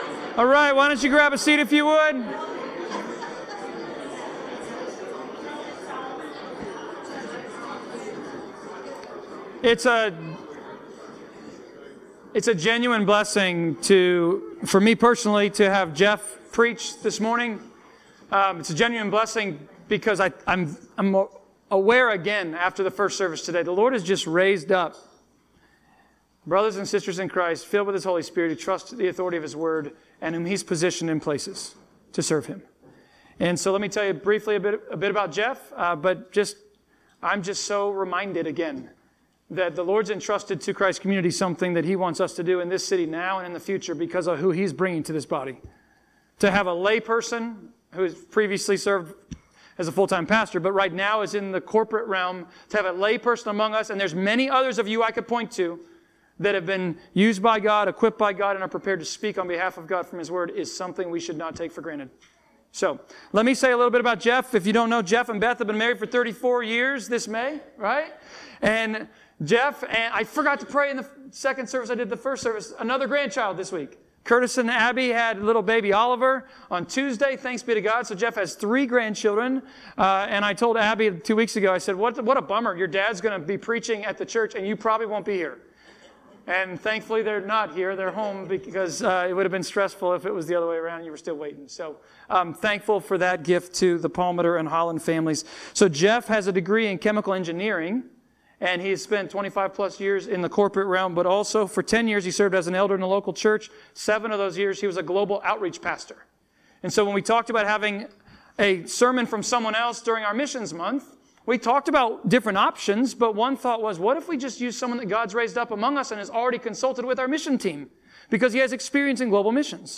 Acts 17.16-34 Service Type: Sermons Topics